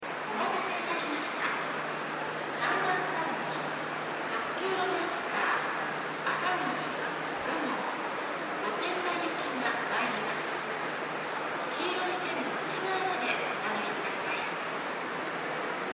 スピーカーも設置されており天井が高いので設置位置も高いです。
３番ホーム特急ロマンスカー
接近放送特急ロマンスカーあさぎり５号　御殿場行き接近放送です。